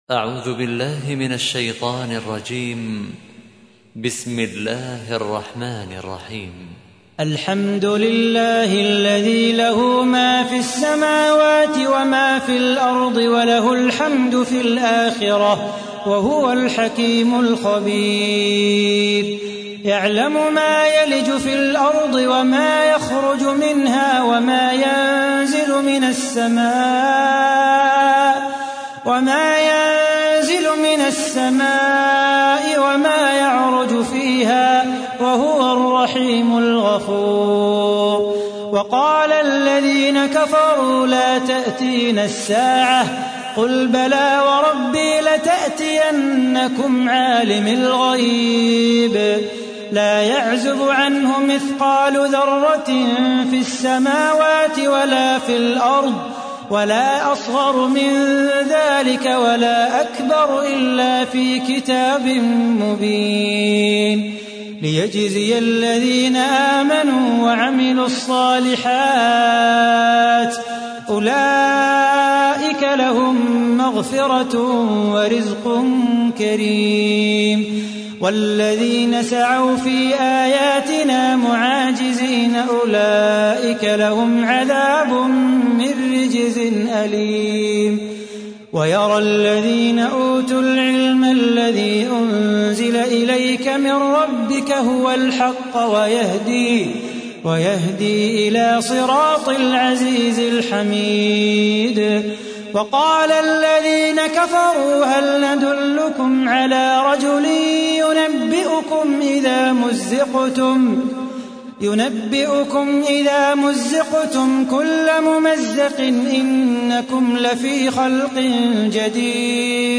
تحميل : 34. سورة سبأ / القارئ صلاح بو خاطر / القرآن الكريم / موقع يا حسين